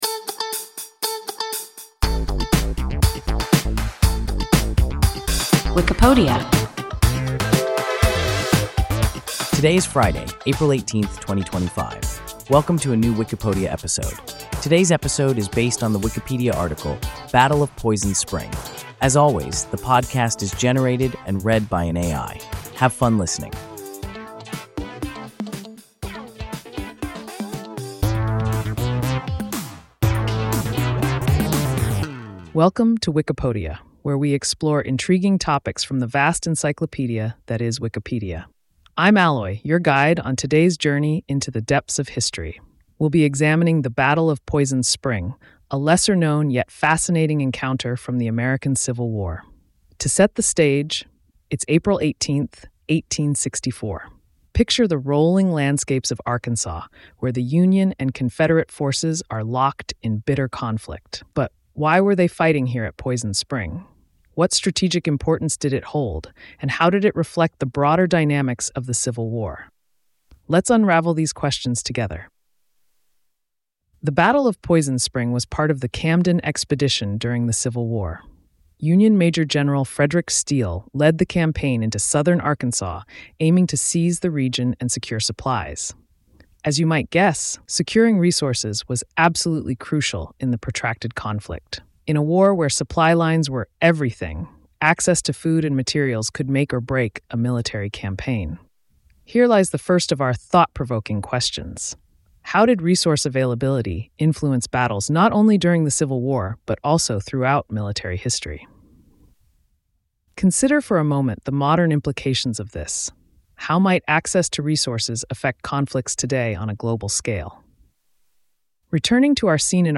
Battle of Poison Spring – WIKIPODIA – ein KI Podcast